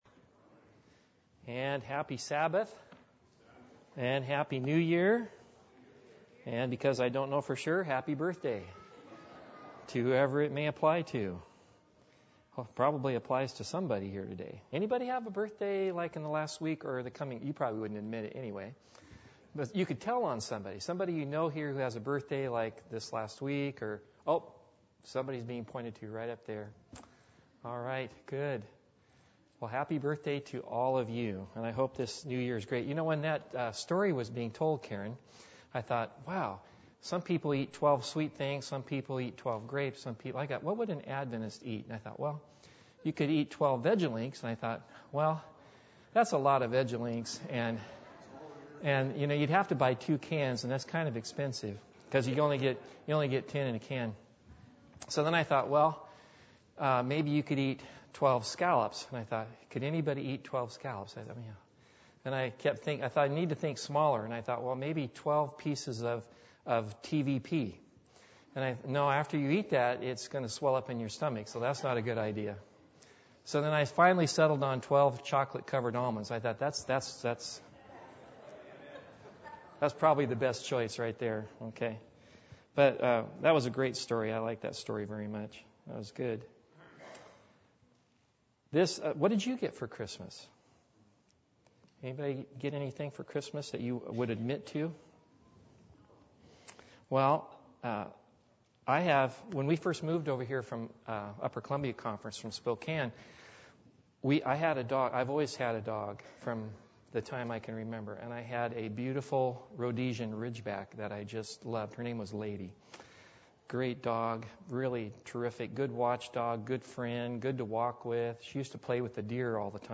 Acts 1:14 Service Type: Sabbath Bible Text